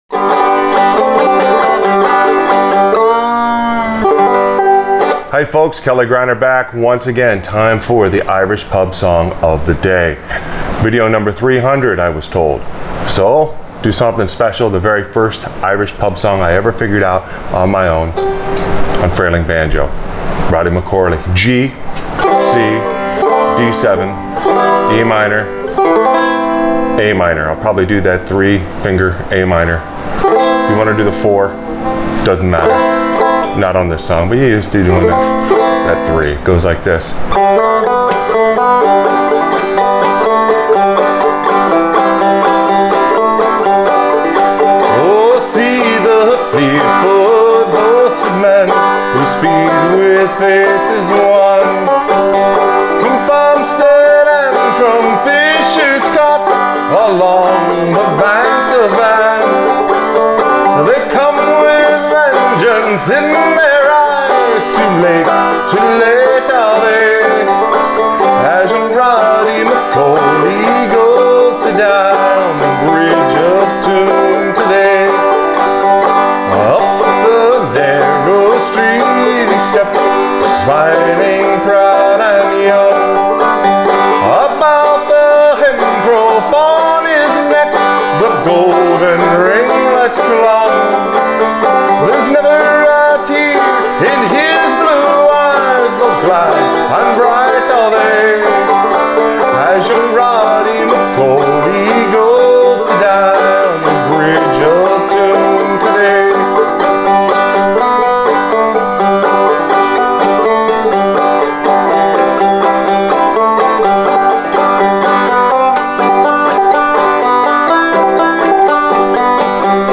Irish Pub Song Of The Day – Frailing Banjo Lesson: Roddy McCorley
Clawhammer BanjoFrailing BanjoInstructionIrish Pub Song Of The Day